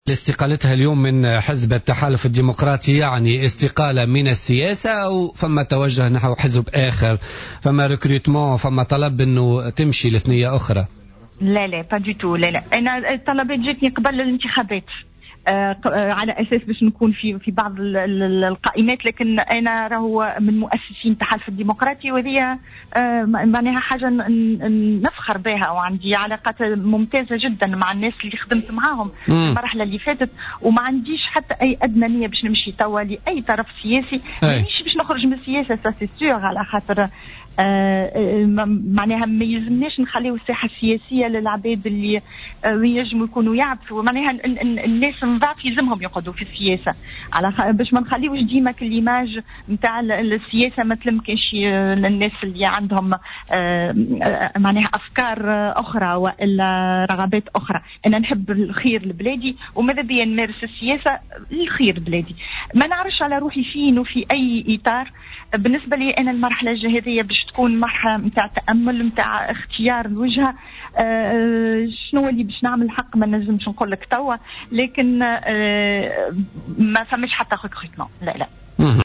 قالت نجلاء بوريال ضيفة برنامج "بوليتيكا" اليوم الاربعاء إن استقالتها من حزب التحالف الديمقراطي جاءت على خلفية انعدام المناخ الملائم للعمل السياسي داخل الحزب،حيث أشارت إلى أن قيادات الحزب عجزت عن ضمان مقومات العمل الناجح للحزب من هيكلة متماسكة وبرامج واضحة.